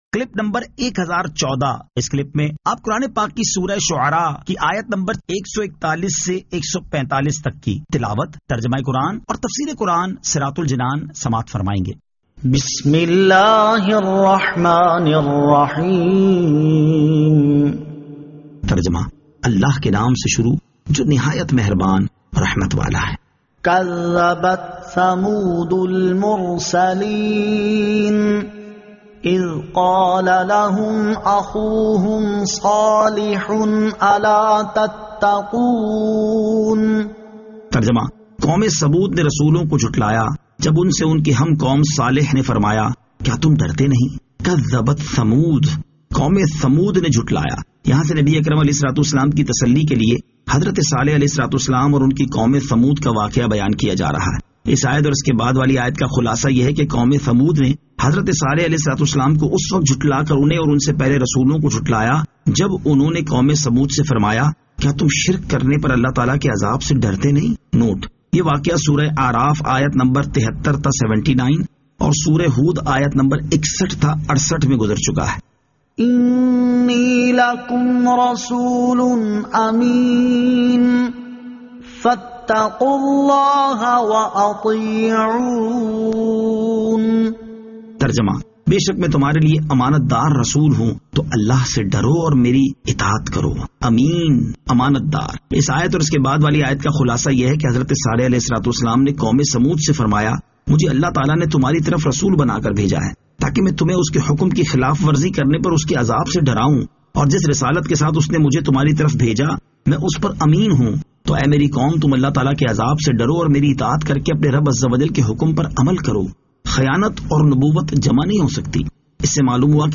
Surah Ash-Shu'ara 141 To 145 Tilawat , Tarjama , Tafseer